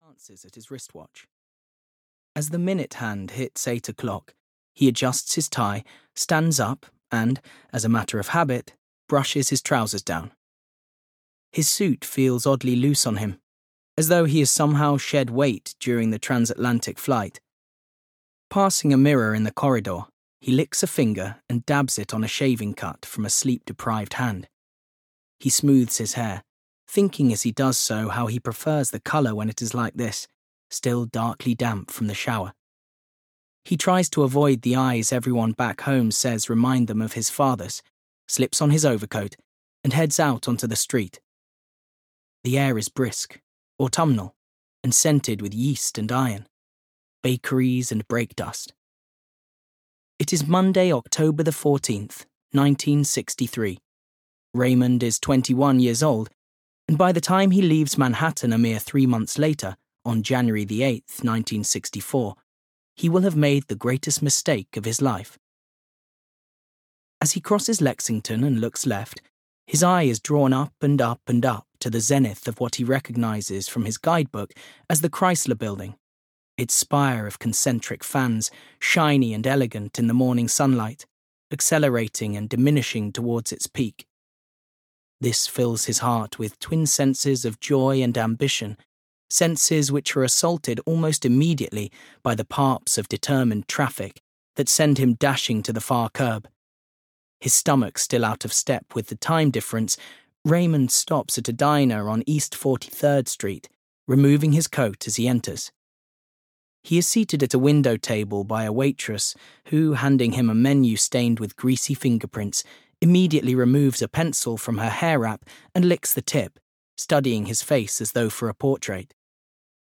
Audio knihaI Am Not Raymond Wallace (EN)
Ukázka z knihy